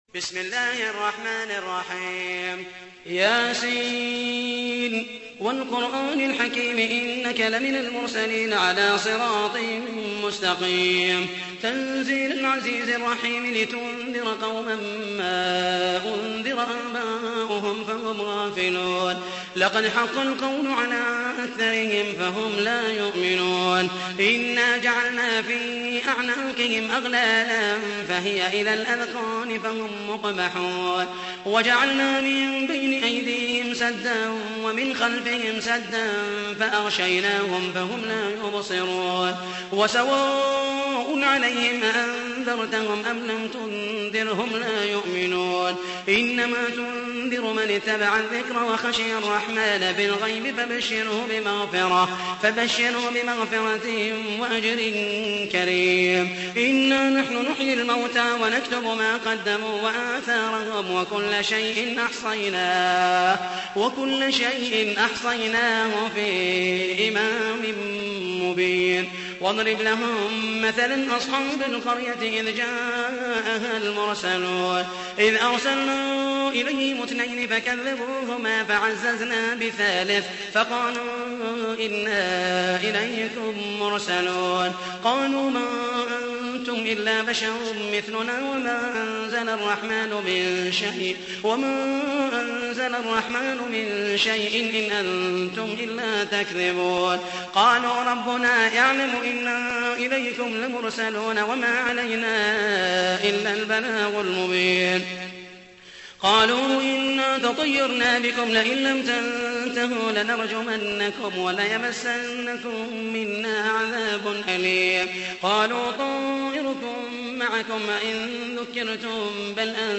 تحميل : 36. سورة يس / القارئ محمد المحيسني / القرآن الكريم / موقع يا حسين